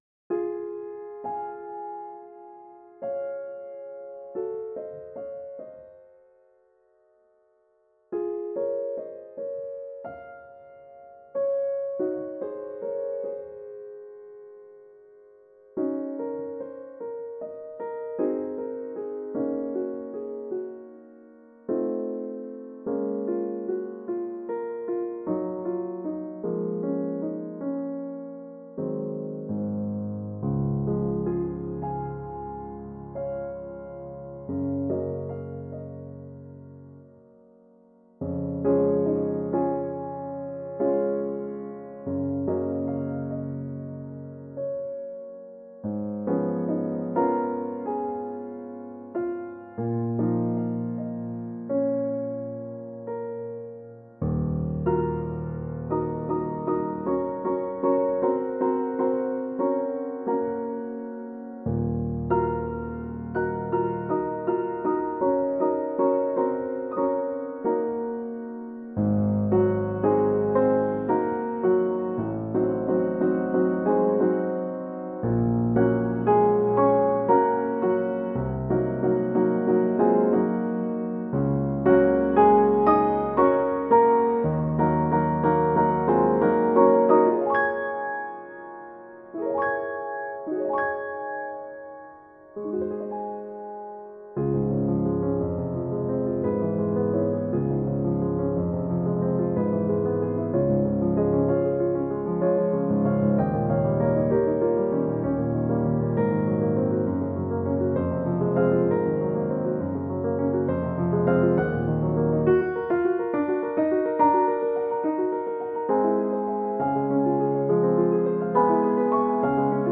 Toujours en stéréo (donc comparable en matière de spatialisation), VSL Vienna Imperial (que je soupçonne de fonctionner comme EWQL), c'est autre chose :